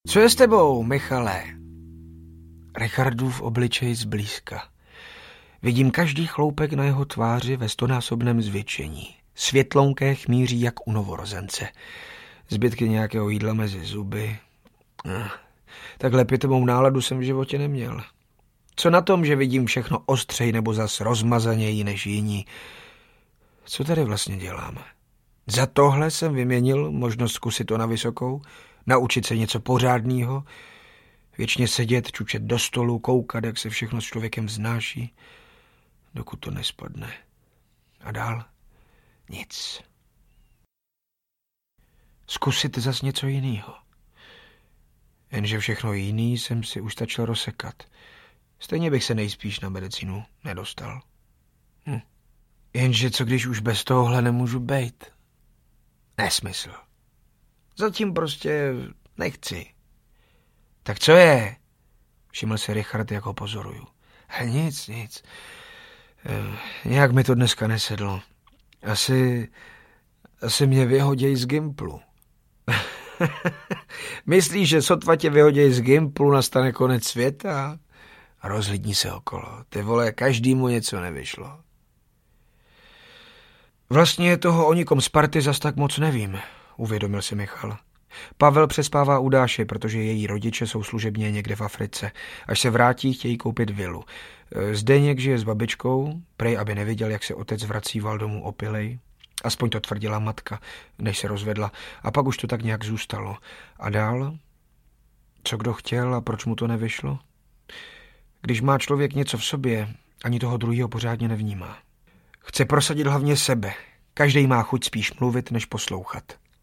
Memento audiokniha
Ukázka z knihy
• InterpretRichard Krajčo